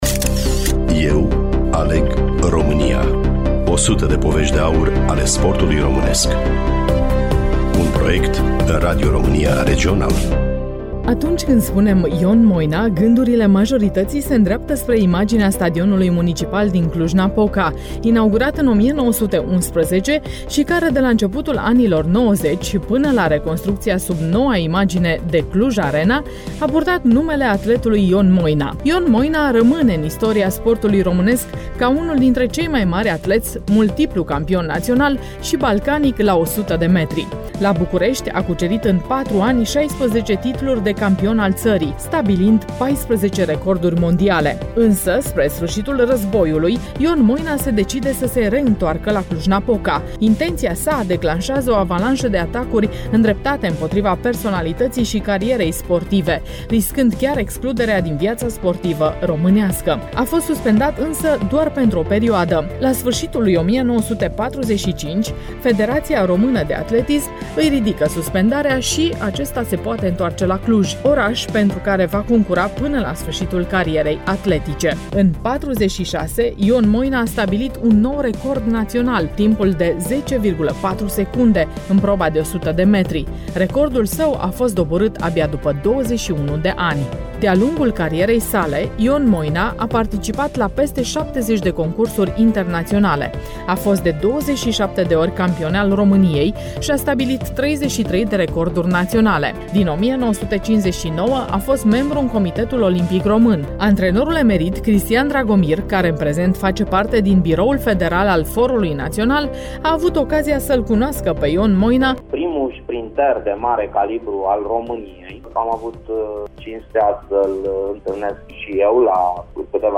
Studioul Radio România Reşiţa